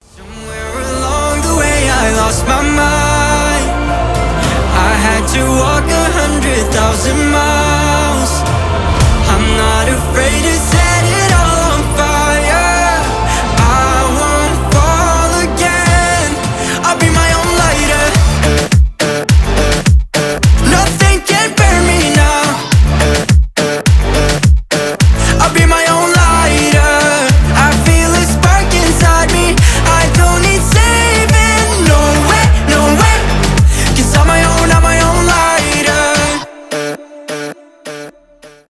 красивый мужской голос , поп